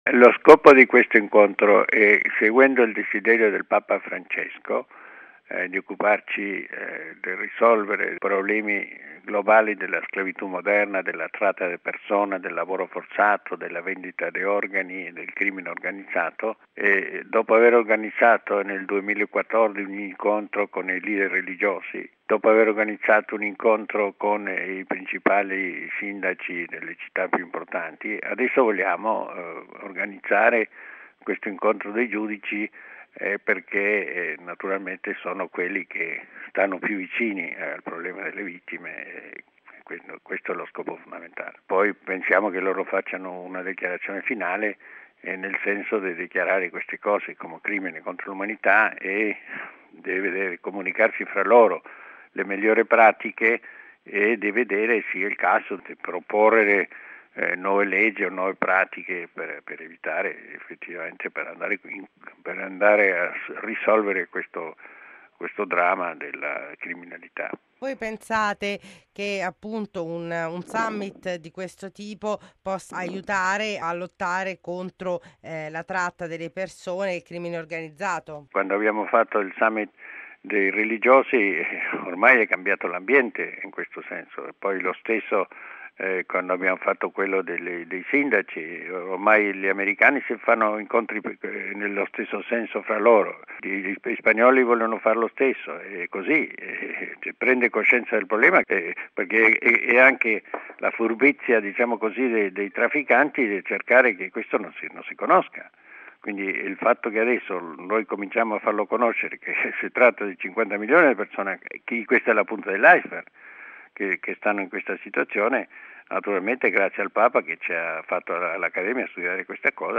Bollettino Radiogiornale del 02/06/2016